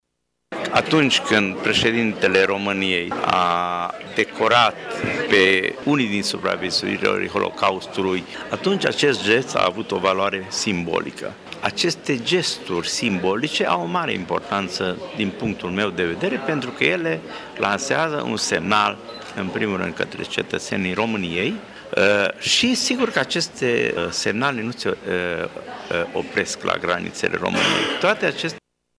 Ceremonia a avut loc astăzi, la Sala de Oglinzi a Palatului Culturii din Tîrgu-Mureș.